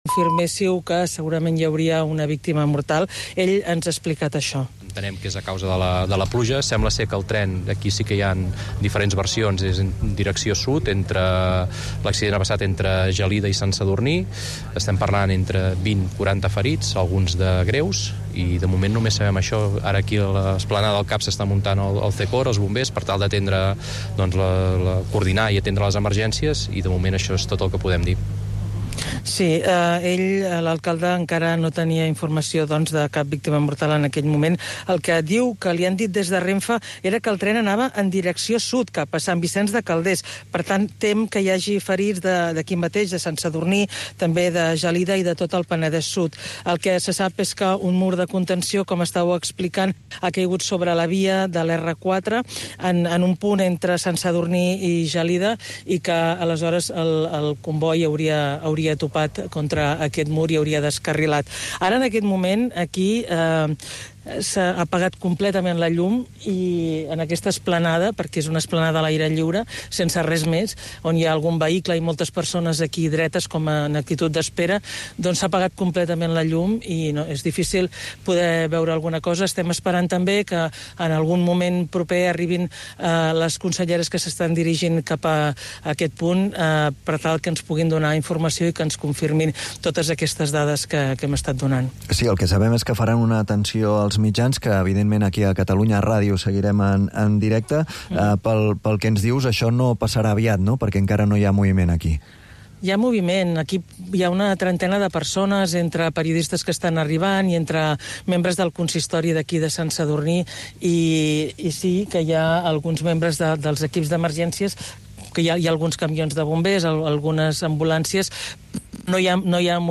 El compromís d'explicar tot el que passa i, sobretot, per què passa és la principal divisa del "Catalunya nit", l'informatiu nocturn de Catalunya Ràdio, dirigit per Manel Alías i Agnès Marquès.